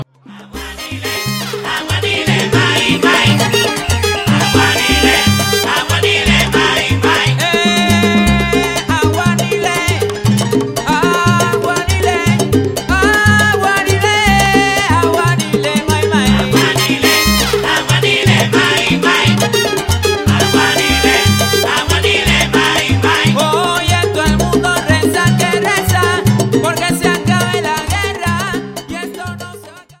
Salsa Charts - Januar 2009